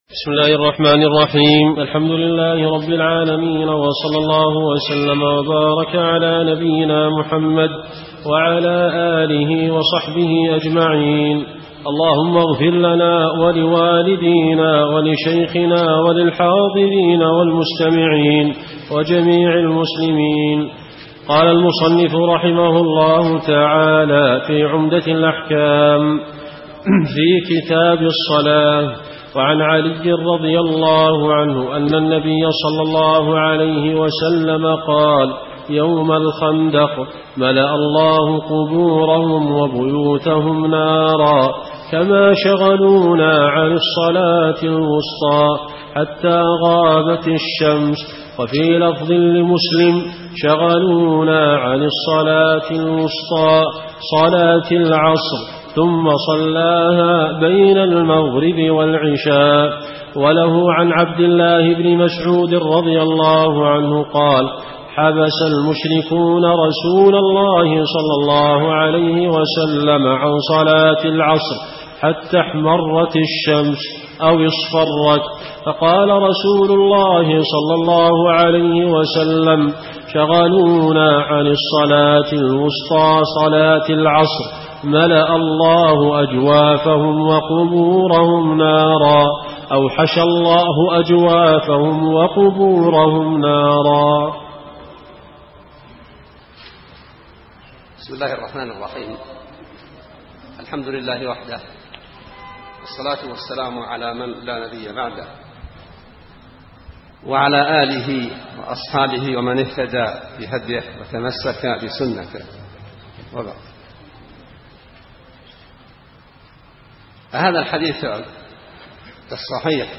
عمدة الأحكام في معالم الحلال والحرام عن خير الأنام شرح الشيخ صالح بن محمد اللحيدان الدرس 7